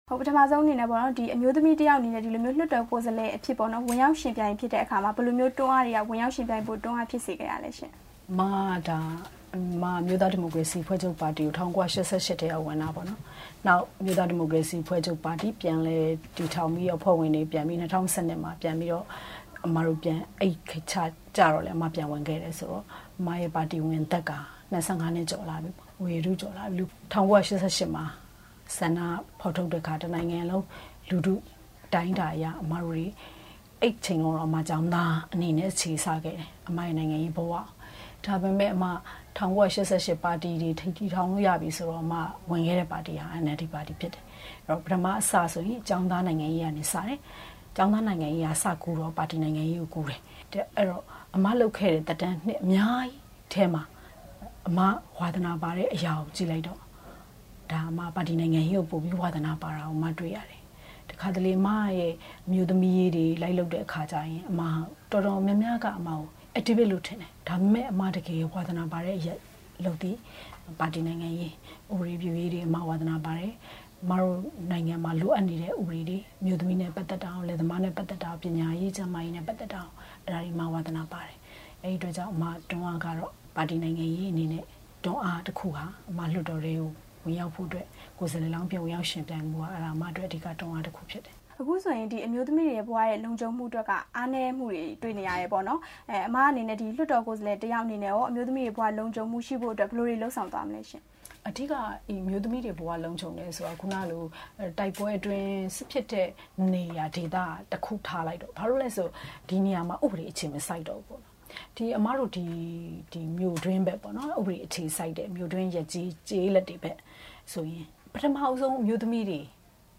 ပြည်သူ့လွှတ်တော်ကိုယ်စားလှယ် ဒေါ်သန္တာနဲ့ မေးမြန်းချက်